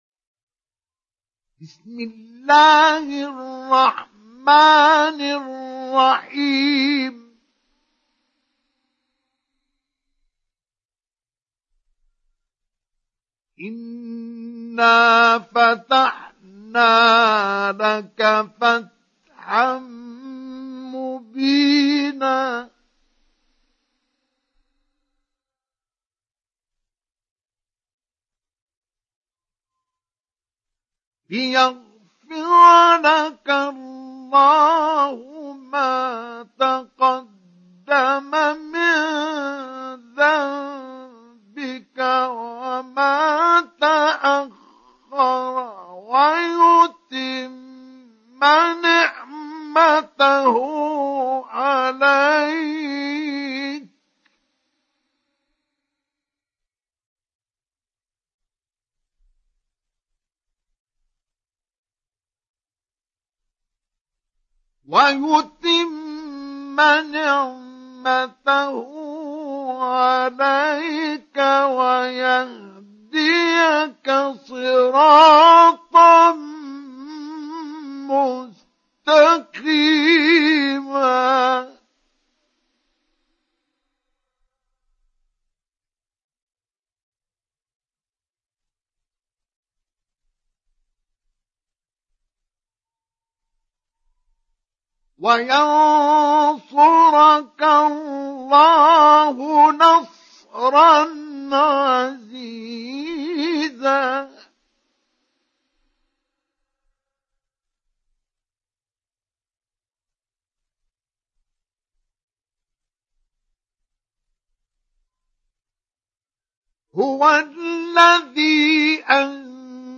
دانلود سوره الفتح مصطفى إسماعيل مجود